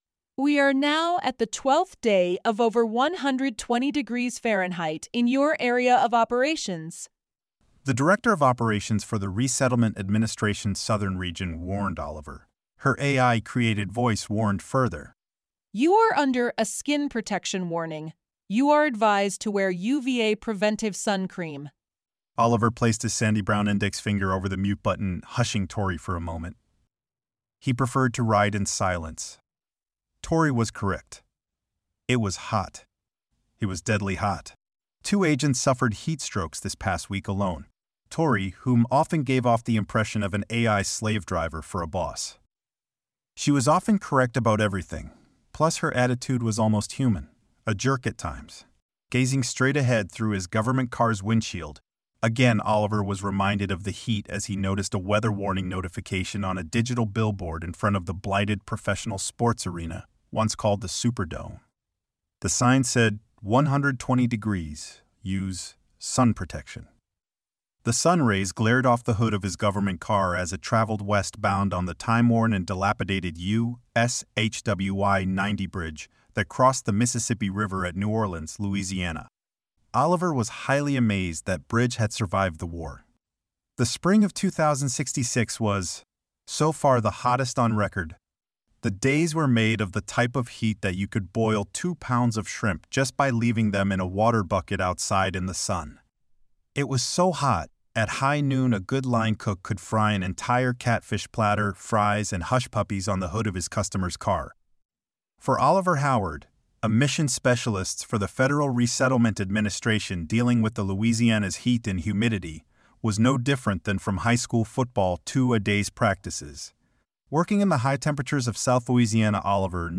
Format: Audio Book
Voices: Machine generated
Narrator: Third Person
Soundscape: Voices only